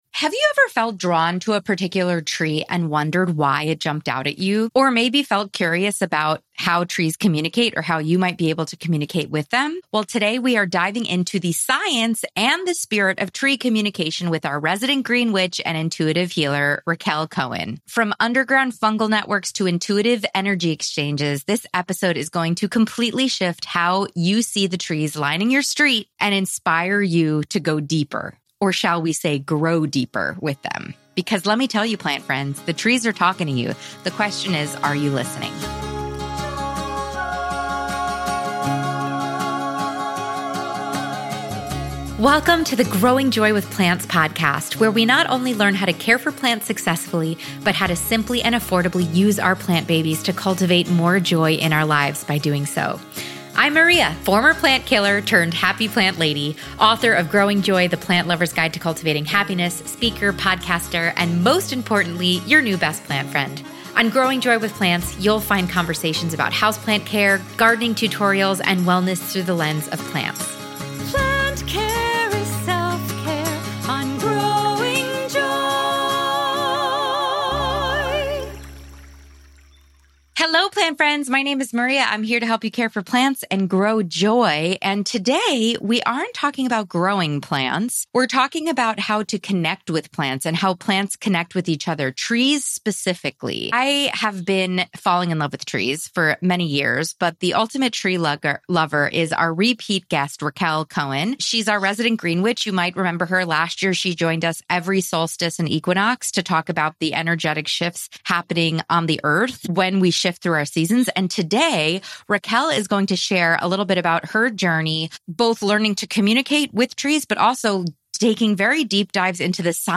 From the underground mycelial networks to intuitive connections and bioelectrical signals, this conversation will change the way you see the trees around you.